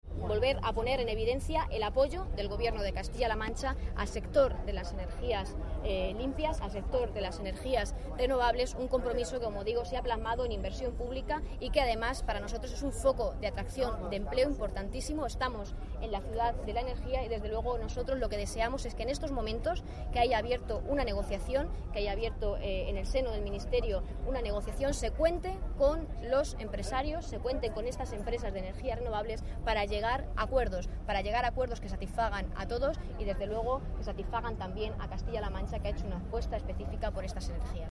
La portavoz del Gobierno de Castilla-La Mancha, Isabel Rodríguez, a preguntas de los periodistas durante la presentación en Puertollano (Ciudad Real) de la campaña itinerante del PSCM-PSOE ‘Sí a Castilla-La Mancha’, aseguró que en materia de agua la política del Ejecutivo castellano-manchego “ha marcado un 3-0” al trasvase Tajo-Segura.
Cortes de audio de la rueda de prensa